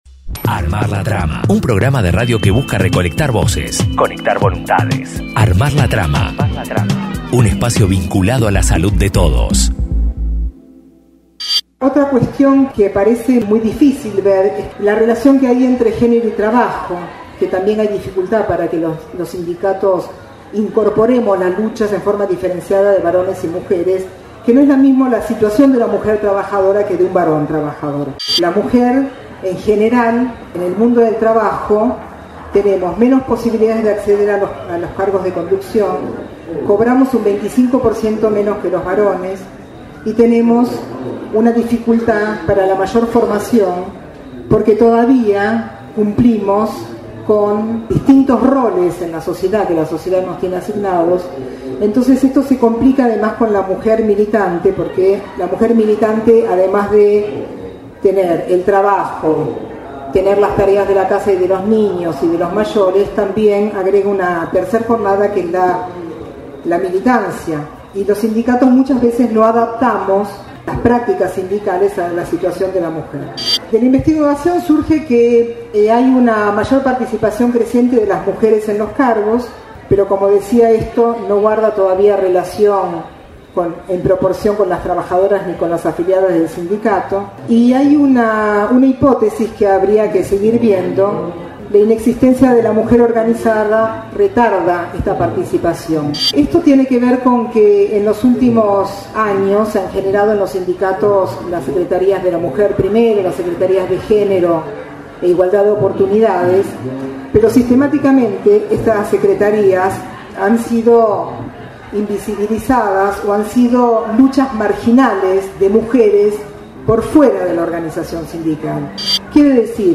Más novedades de la !er Feria del Libro del Movimiento de Trabajadores y Trabajadoras